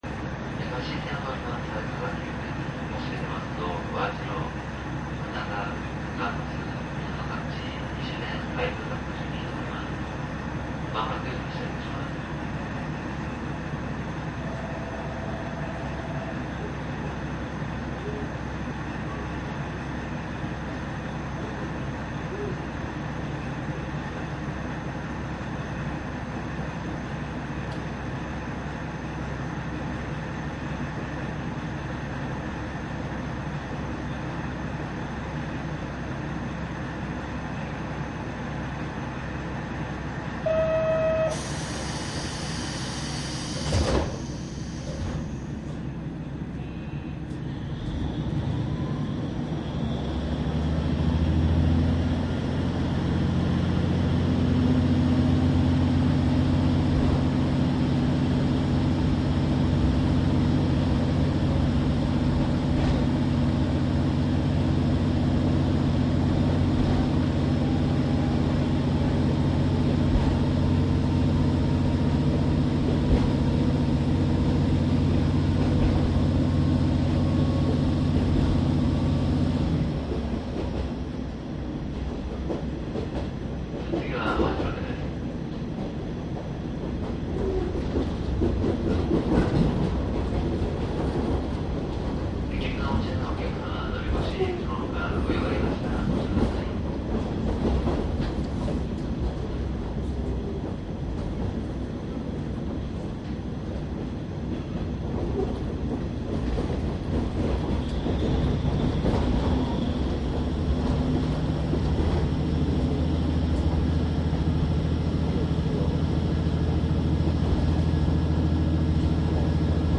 香椎線は半島区間の博多～西戸崎の録音です。
キハ47はゼロ番台としんぺい用の9000番台でエンジンの音が違います。
■【普通】博多→西戸崎→香椎 キハ47－72
サンプル音声 キハ４７-72 .mp3
マスター音源はデジタル44.1kHz16ビット（マイクＥＣＭ959）で、これを編集ソフトでＣＤに焼いたものです。